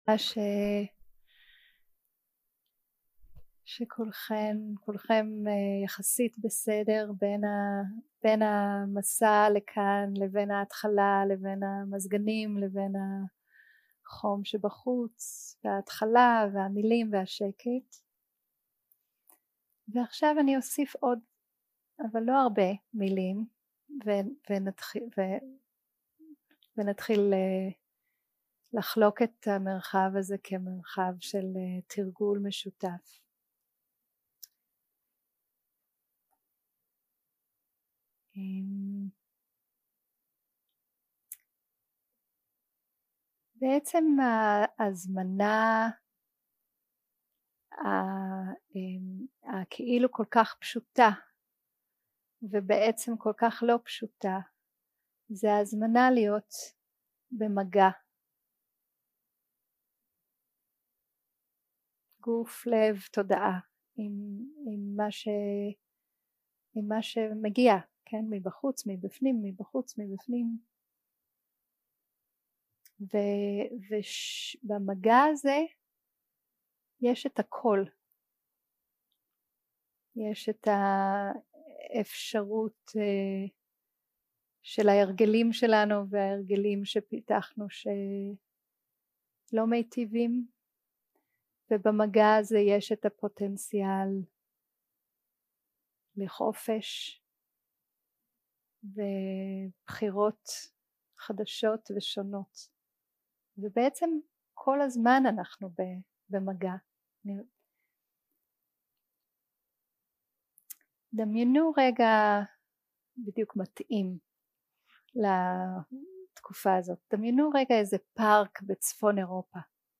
יום 1 – הקלטה 1 – ערב – מדיטציה מונחית ושיחת דהארמה – הזמנה לתרגול
יום 1 – הקלטה 1 – ערב – מדיטציה מונחית ושיחת דהארמה – הזמנה לתרגול 0:00 0:00 סוג ההקלטה: Dharma type: Dharma Talks שפת ההקלטה: Dharma talk language: Hebrew